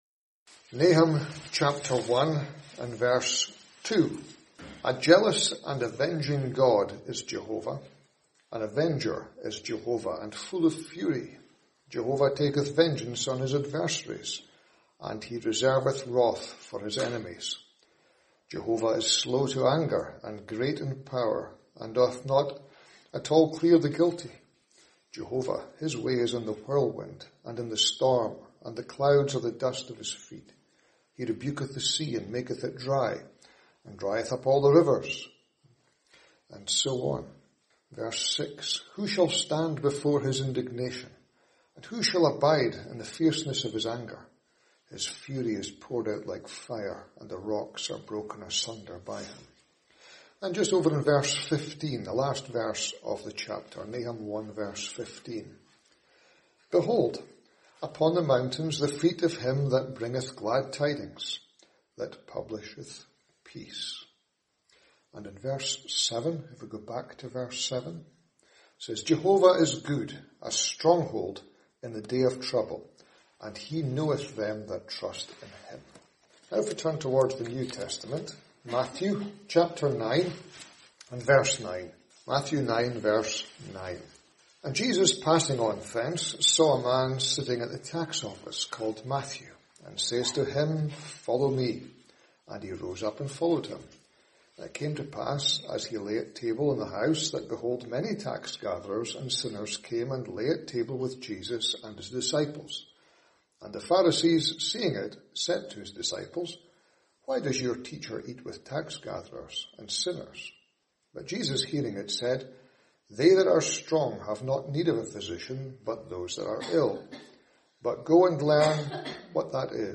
In this Gospel preaching, you will hear about a place of safety that can be found in the person of the Lord Jesus Christ.